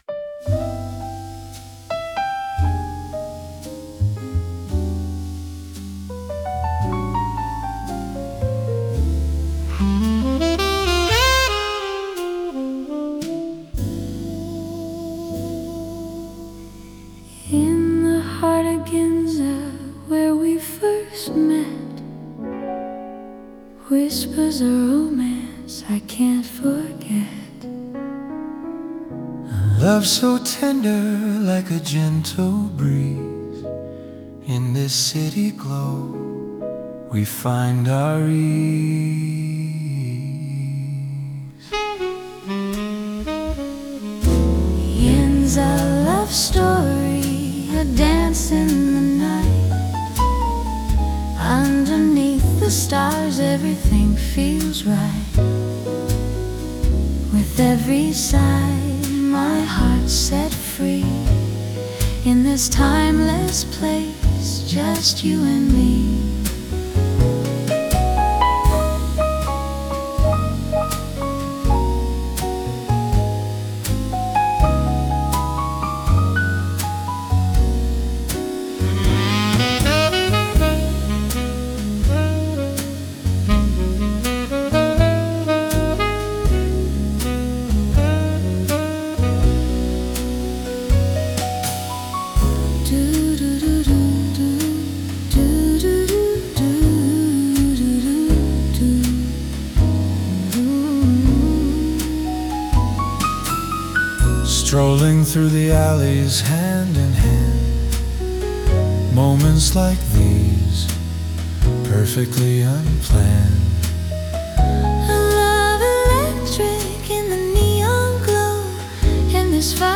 Note: This song was generated by AI.